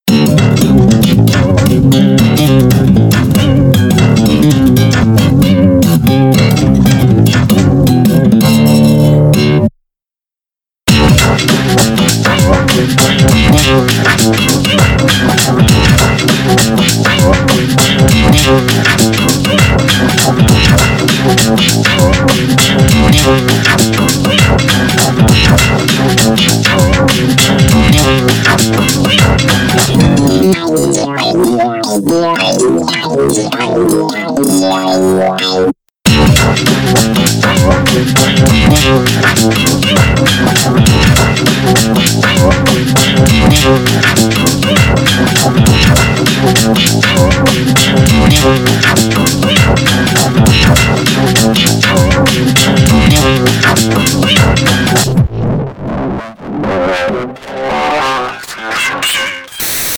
mini funk jam
Stihovi mini instrumental